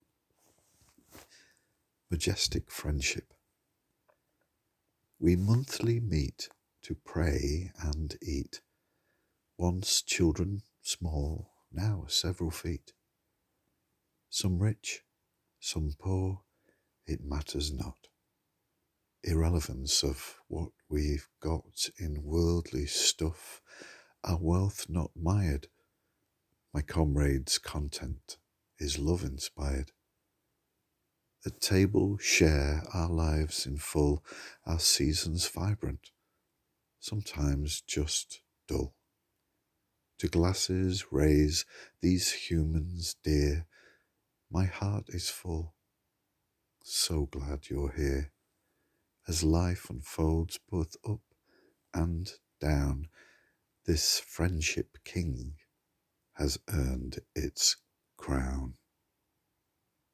You read so well.